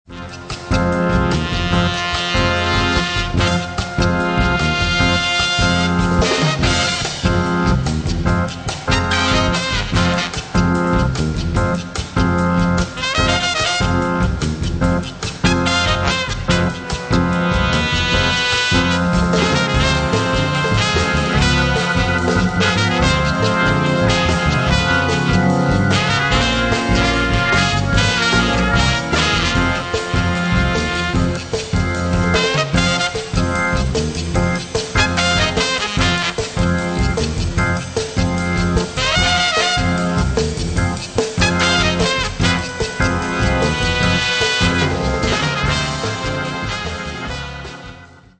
Lounge-Jazz-Combo